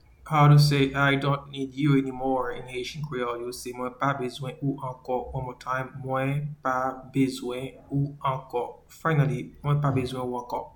Pronunciation:
I-dont-need-you-anymore-in-Haitian-Creole-Mwen-pa-bezwen-ou-anko-1.mp3